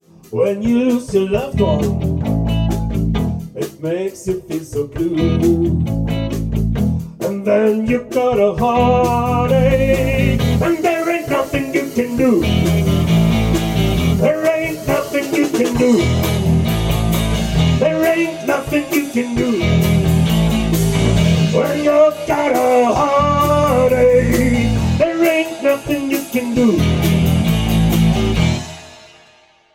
Hier eine völlig unbearbeitete Hörprobe aus dem Proberaum